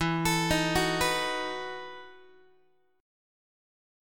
EM7sus2sus4 Chord
Listen to EM7sus2sus4 strummed